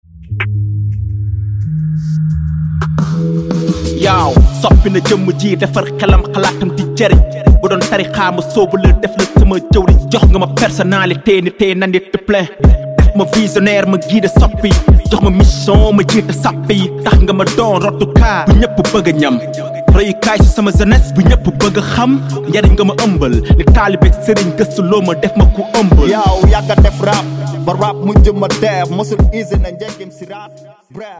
Un album 100% Rap mêlant émotions, poésie et mélodies